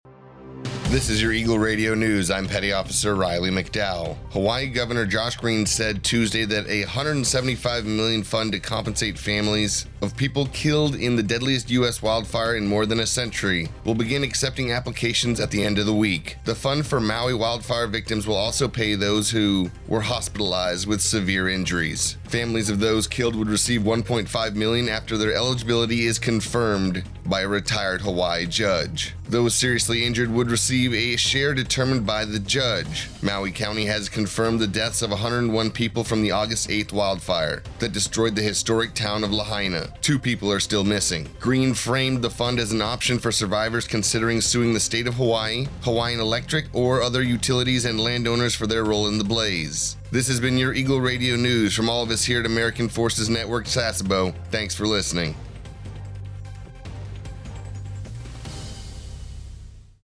A TFNewscast for AFN Sasebo's radio about the Hawaiian Gov. Josh Green saying that a $175 million fund to compensate families of people killed in the deadliest U.S. wildfire in more than a century will begin accepting applications.